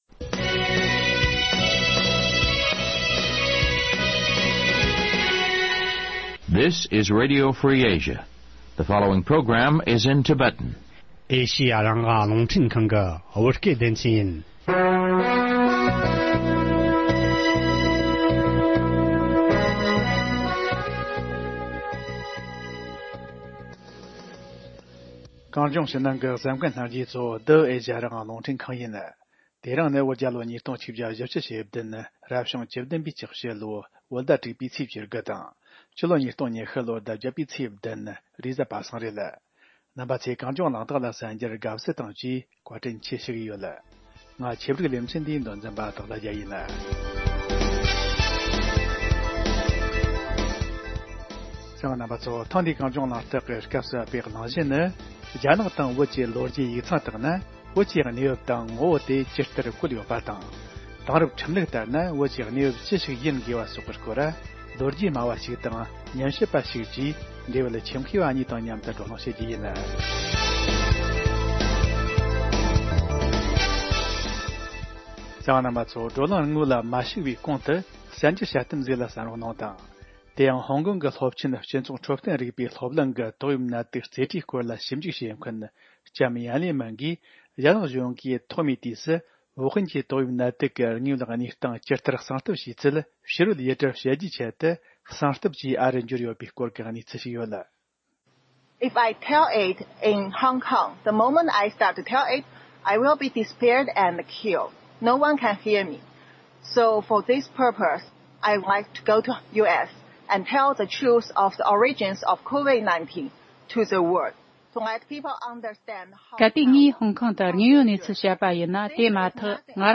རྒྱ་ནག་གི་ལོ་རྒྱུས་ཡིག་ཚང་དང་བོད་རང་གི་ལོ་རྒྱུས་དེབ་ཐེར་སོགས་སུ་བོད་ཀྱི་གནས་བབ་ཇི་ལྟར་བཀོད་ཡོད་པ་སོགས་ཀྱི་ཐད་བགྲོ་གླེང་ཞུས་པ།